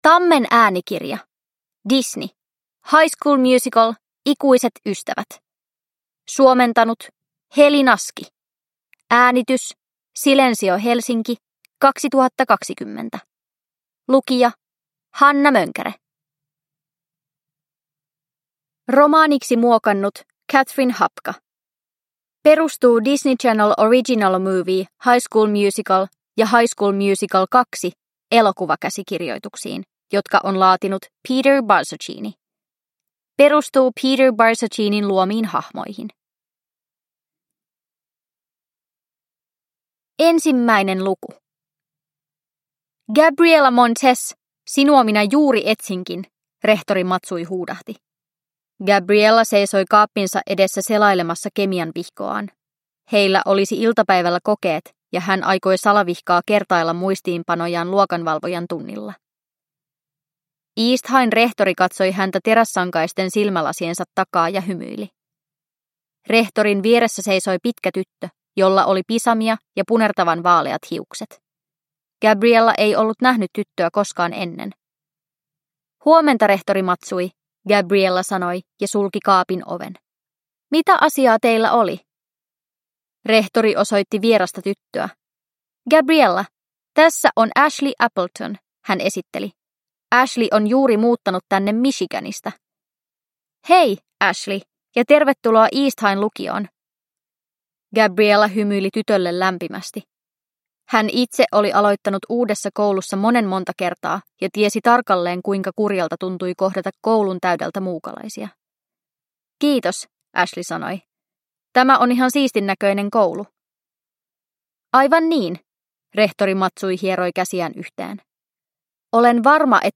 High School Musical. Ikuiset ystävät? – Ljudbok – Laddas ner